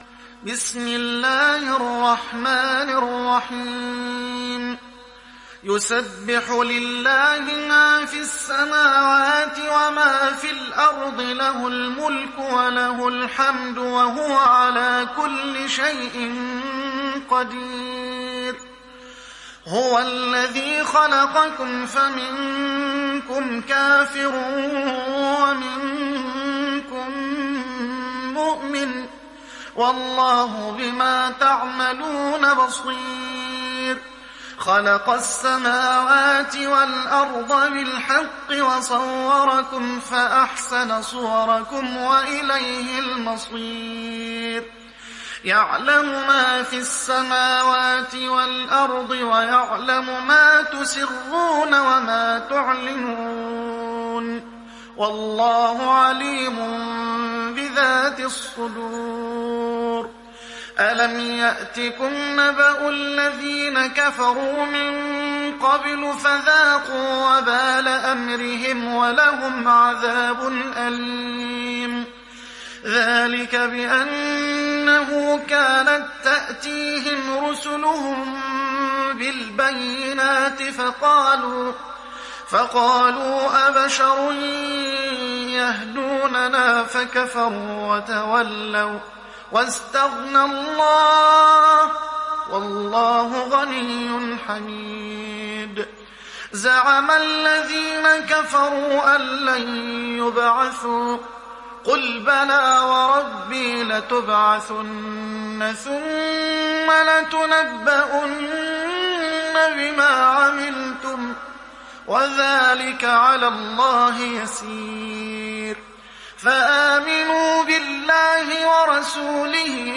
دانلود سوره التغابن mp3 محمد حسان روایت حفص از عاصم, قرآن را دانلود کنید و گوش کن mp3 ، لینک مستقیم کامل